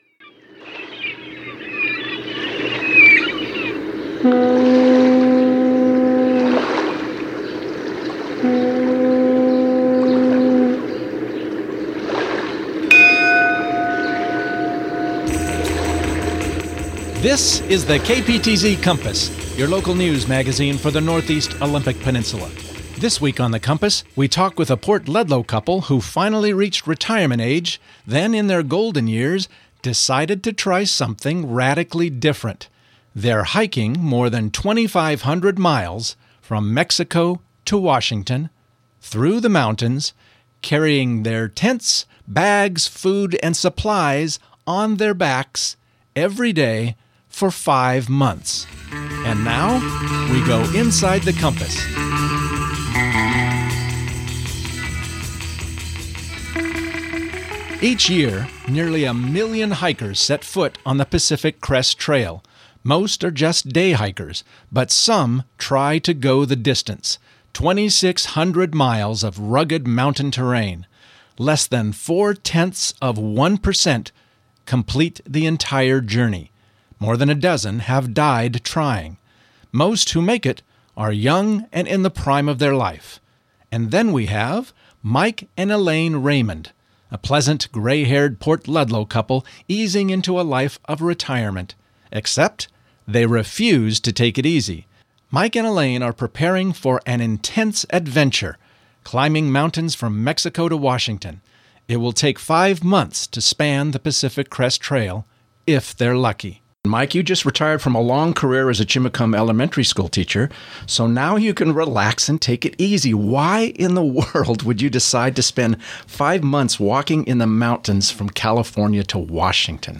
KPTZ Interview